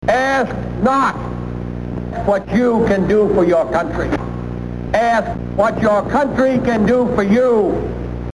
Title: zvrst.3 / audio trash - collected
Audio net art work only.
The voice of a leader breaks into many voices and his words splinter into many sounds.
The multiplicity of echoes and other sound effects, that the listner co-creates, reverberate and penetrate the viewer on a subliminal, almost organic level.